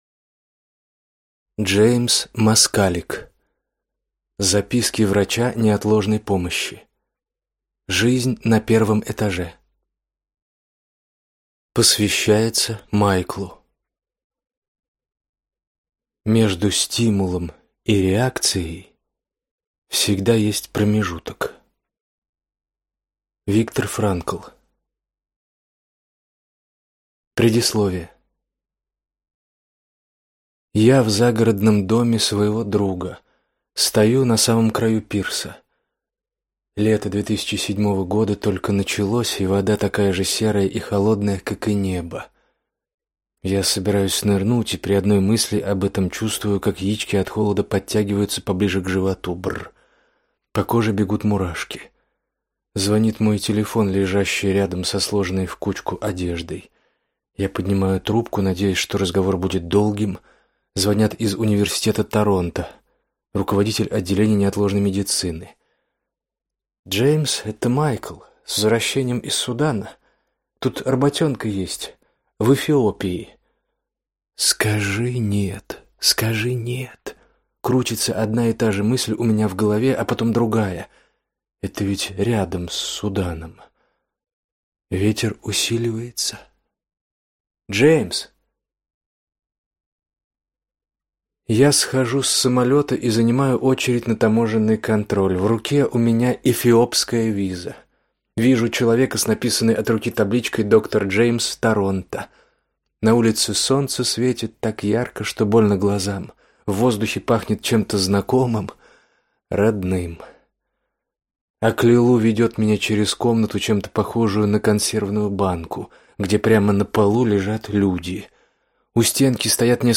Аудиокнига Записки врача неотложной помощи. Жизнь на первом этаже | Библиотека аудиокниг